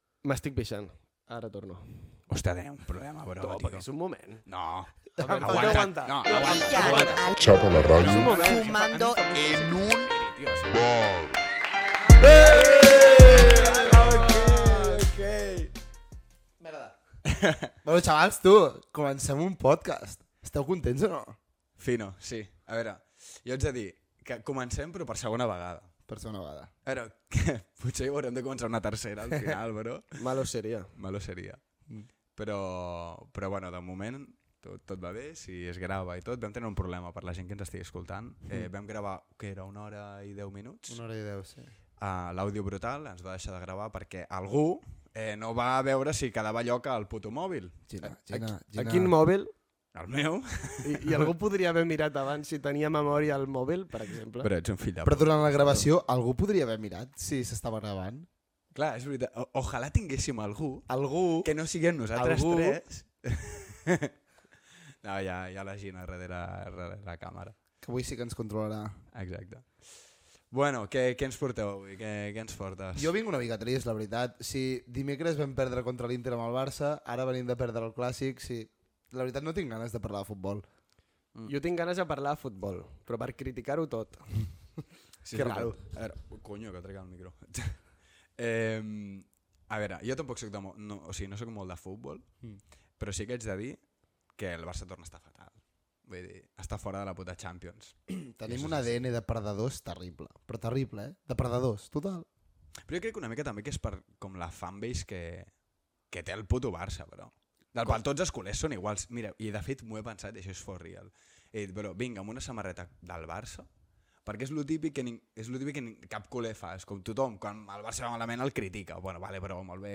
Identificació del programa, comentari sobre un incident tècnic que havien tingut, diàleg sobre l'actualitat del Futbol Club Barcelona i sobre el tall de cabell d'un dels presentadors Gènere radiofònic Entreteniment